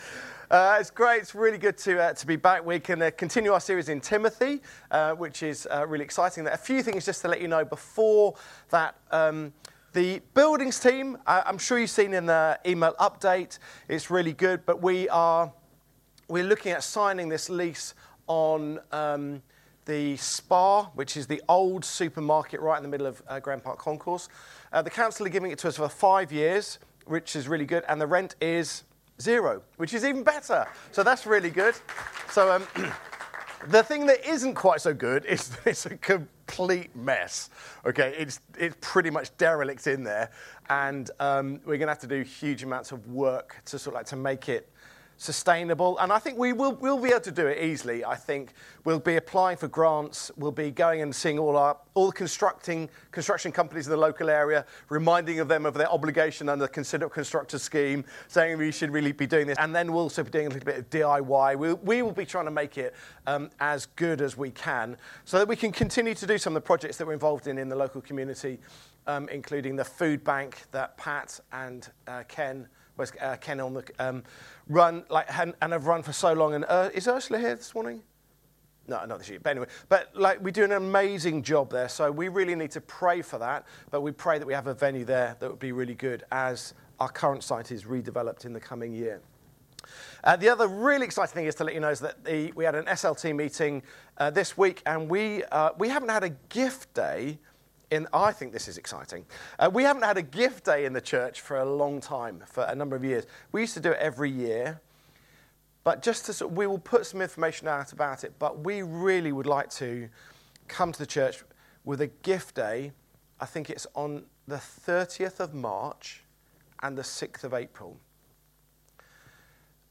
Download Elders | Sermons at Trinity Church